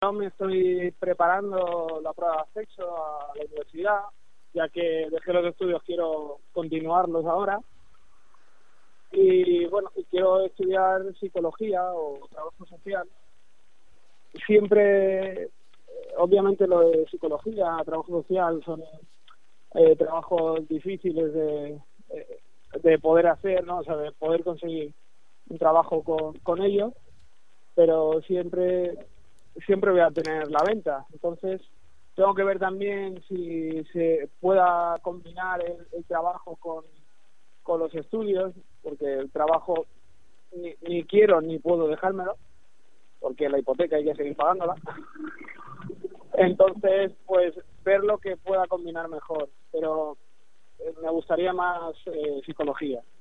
pero sí me gustaría estudiar Psicología formato MP3 audio(1,02 MB), explica ilusionado.